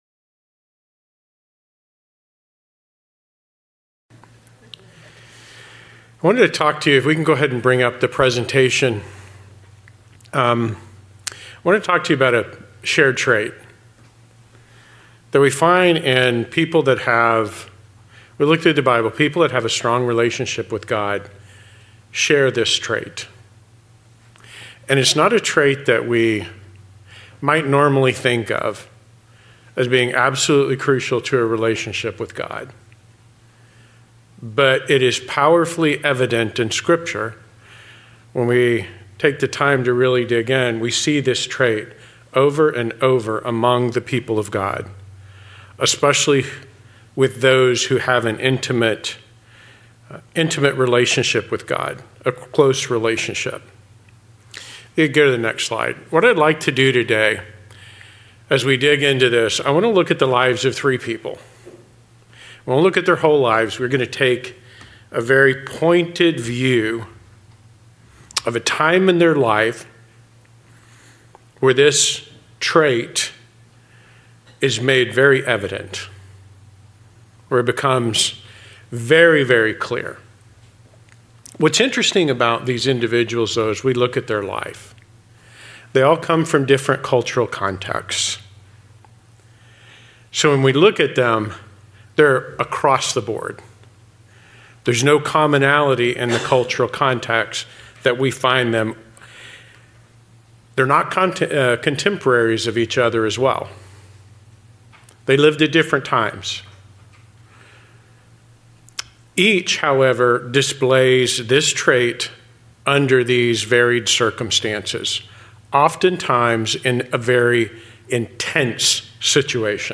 Given in Las Vegas, NV Redlands, CA San Diego, CA